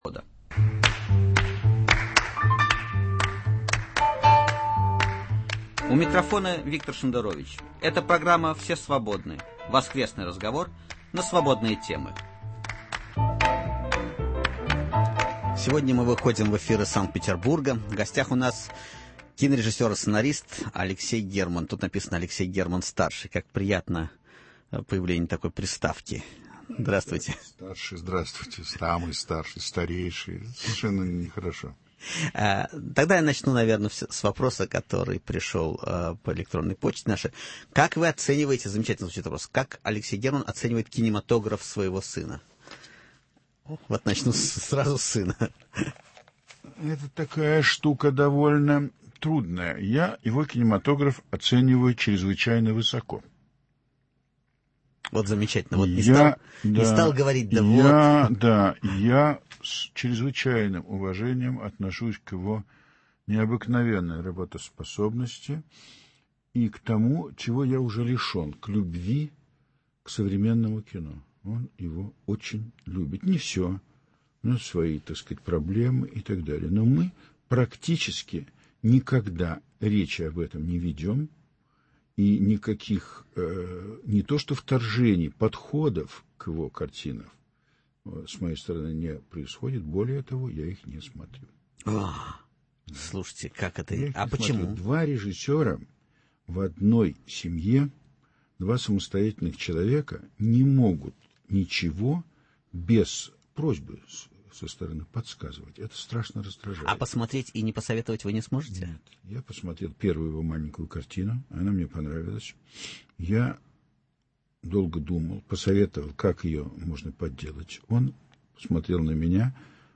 В гостях у Виктора Шендеровича – известный российский кинорежиссер и сценарист Алексей Герман («Двадцать дней без войны», «Проверка на дорогах», «Мой друг Иван Лапшин», «Хрусталев, машину!»)